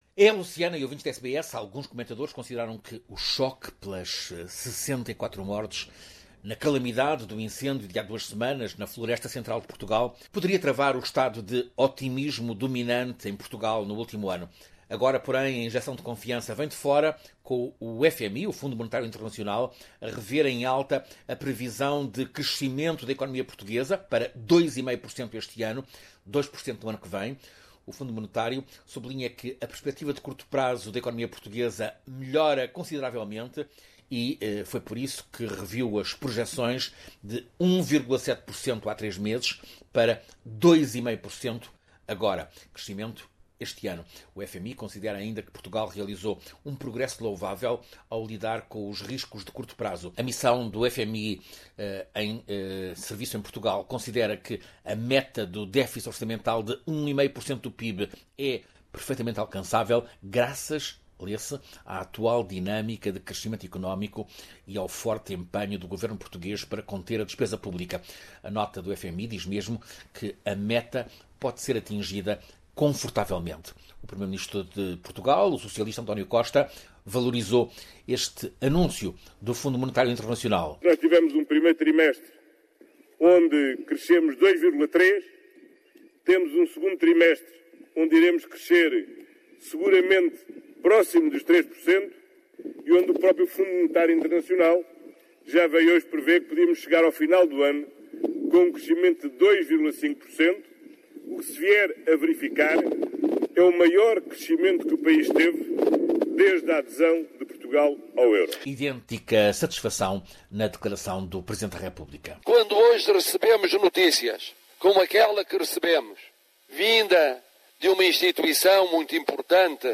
O FMI eleva a previsão de crescimento da economia portuguesa para 2,5% e faz elogios ao processo de recuperação do país. Ouça reportagem